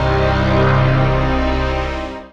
DM PAD2-76.wav